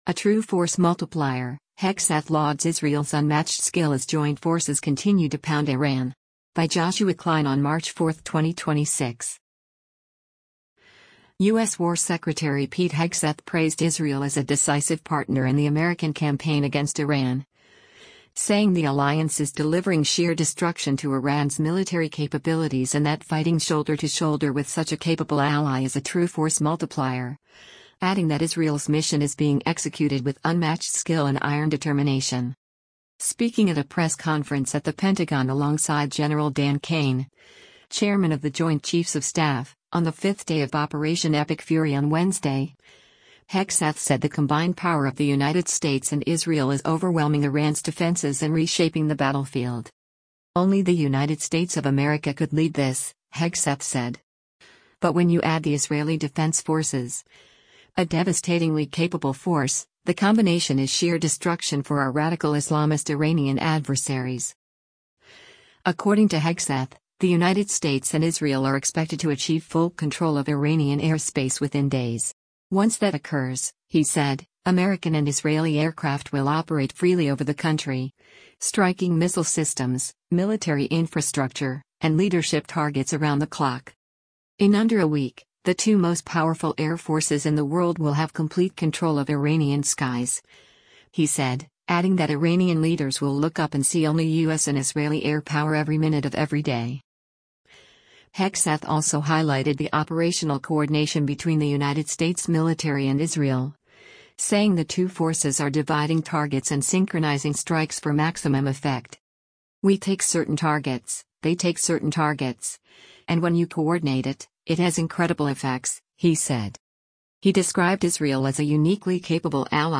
Speaking at a press conference at the Pentagon alongside Gen. Dan Caine, chairman of the Joint Chiefs of Staff, on the fifth day of Operation Epic Fury on Wednesday, Hegseth said the combined power of the United States and Israel is overwhelming Iran’s defenses and reshaping the battlefield.